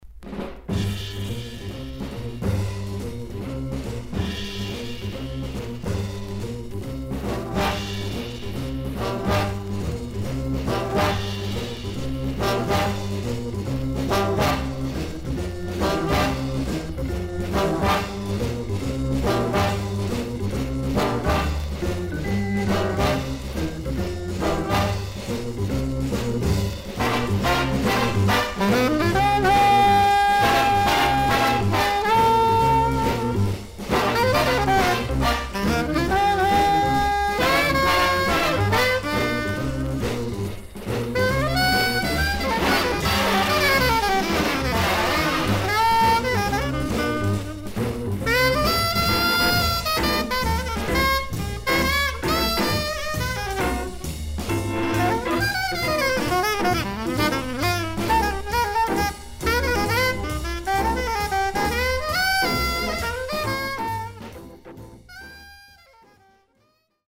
ジャズ・サックス奏者。
VG++ 少々軽いパチノイズの箇所あり。クリアな音です。